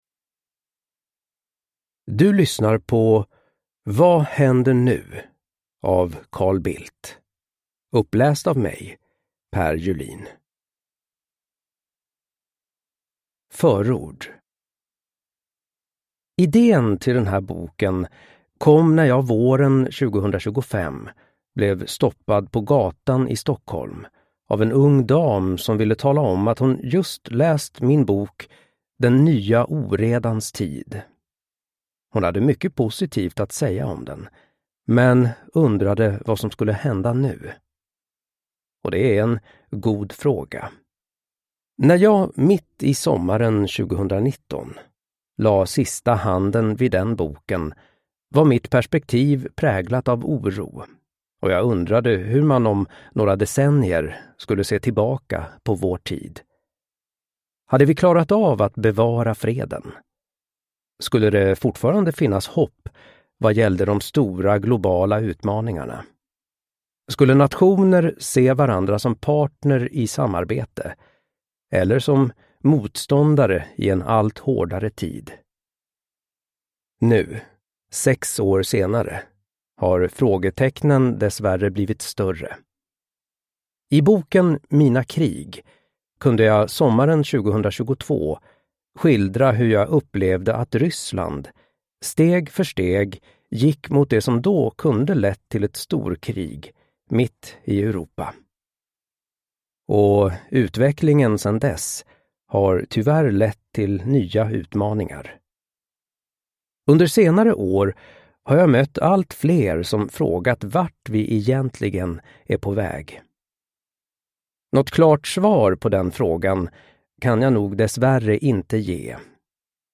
Vad händer nu? : I den nya oredans tid (ljudbok) av Carl Bildt